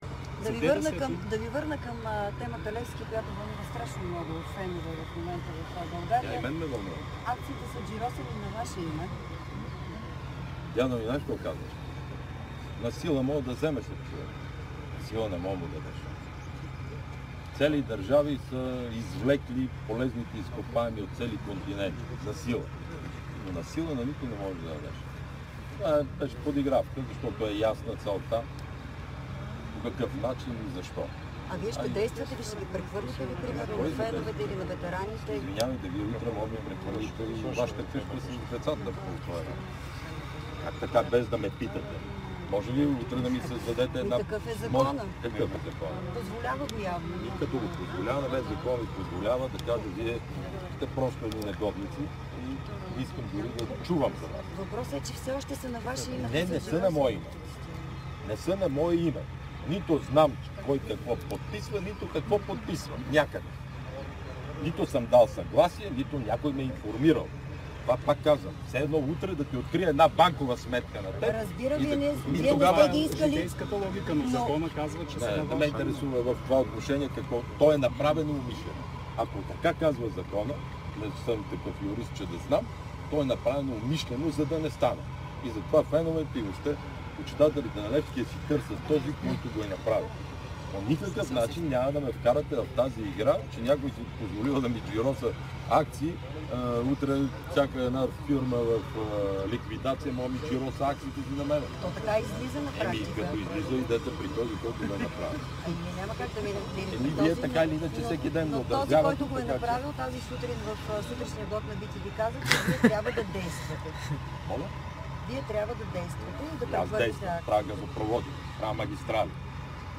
Бойко Борисов най-накрая проговори за джиросаните акции на Левски по време на инспекцията си на интерконектора Гърция България, заедно с министъра на енергетиката Теменужка Петкова, Министър-председателят заяви, че няма никакво намерение да влиза в "тези игри" и да предприема каквото и да било с акциите на футболния клуб.